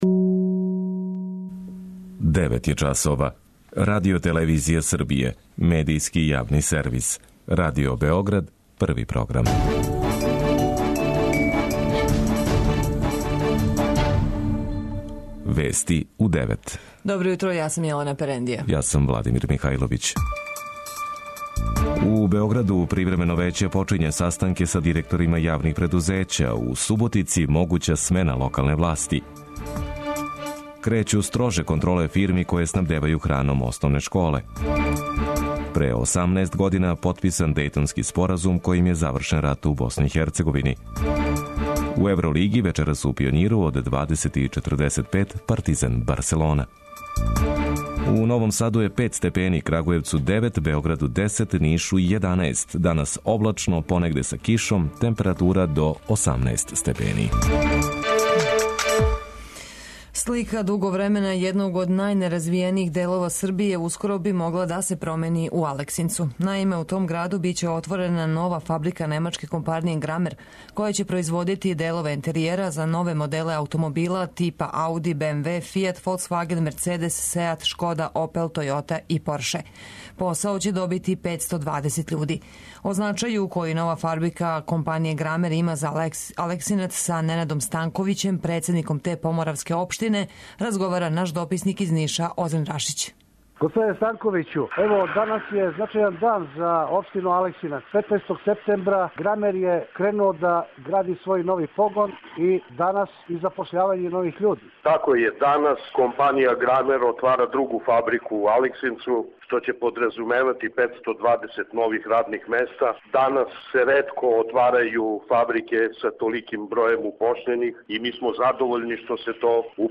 Вести уређују и воде